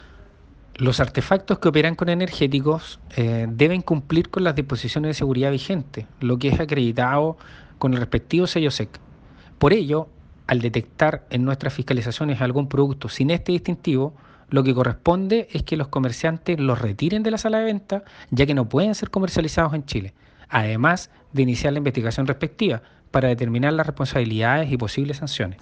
Audio: Víctor Pérez Ortega, Director Regional de SEC Ñuble